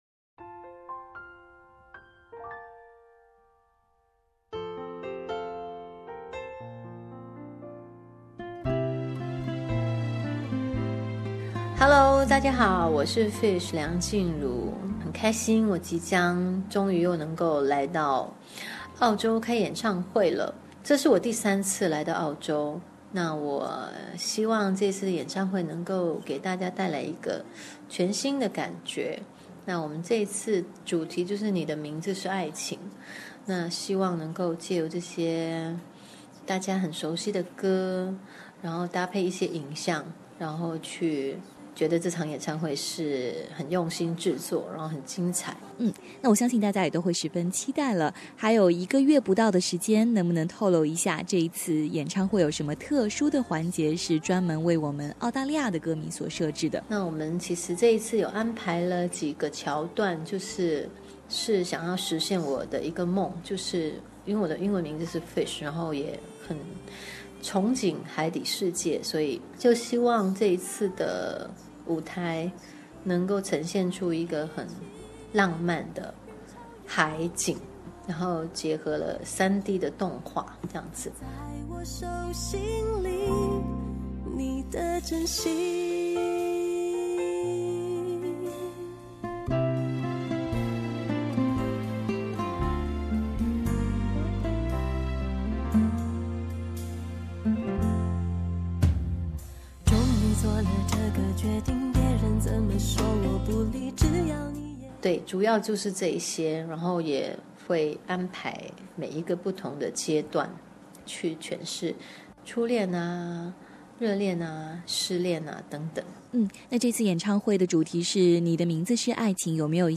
在今天我们请到梁静茹做客SBS普通话节目